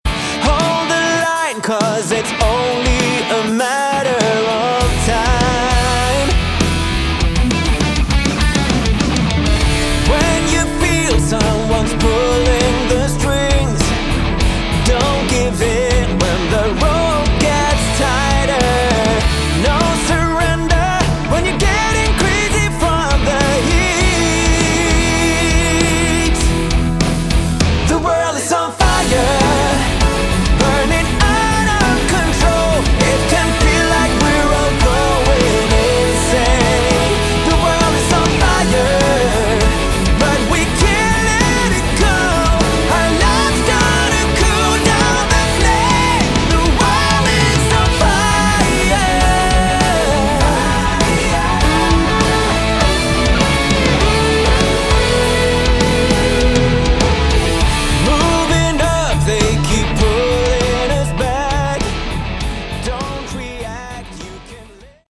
Category: Melodic Rock
lead & backing vocals
guitars
piano & keyboards
bass
drums
Uplifting and extremely melodic as you'd expect.